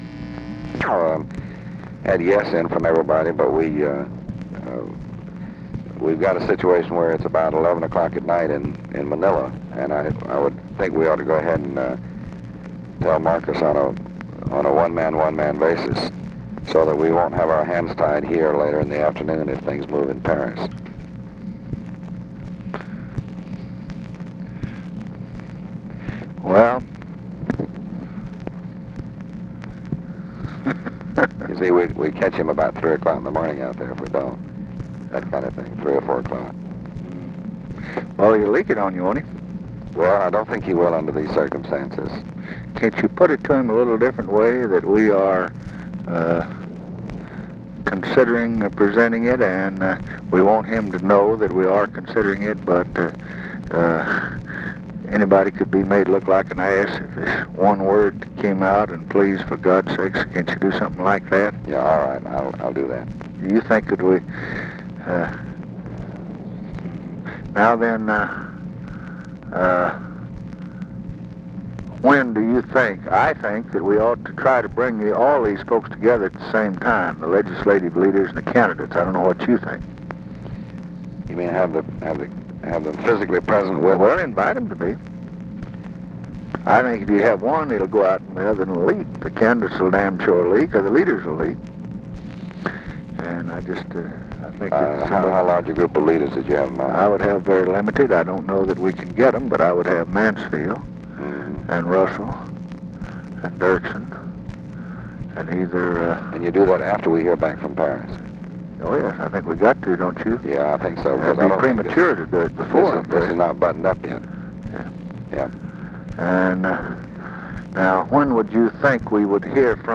Conversation with DEAN RUSK, October 15, 1968
Secret White House Tapes